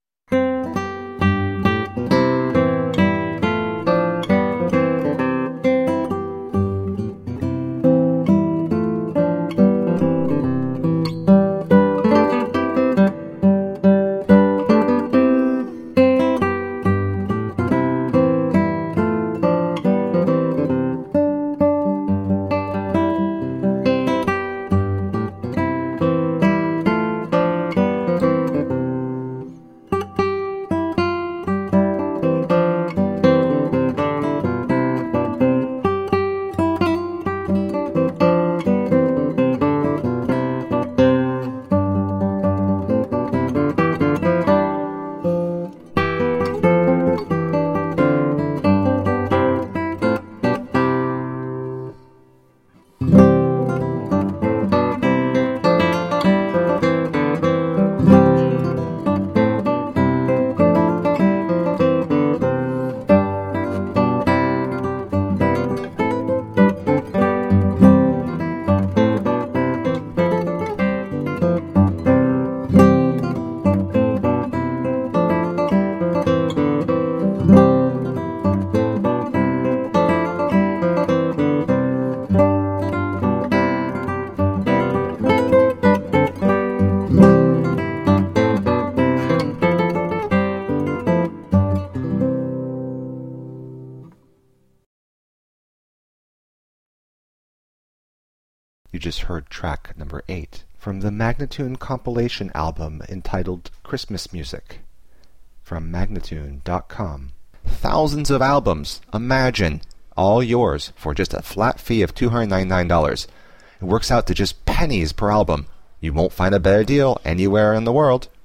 carols
simple acoustic guitar